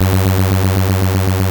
ihob/Assets/Extensions/RetroGamesSoundFX/Hum/Hum32.wav at master
Hum32.wav